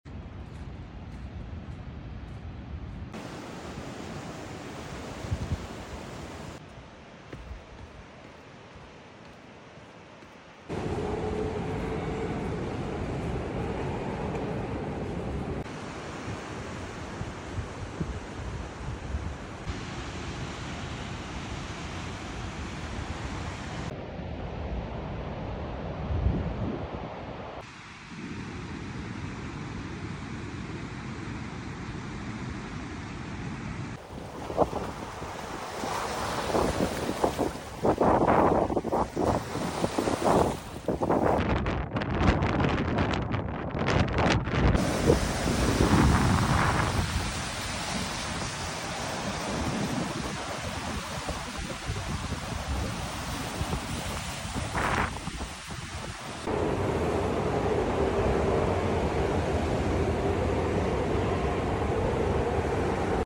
Sounds if the Oregon Coast sound effects free download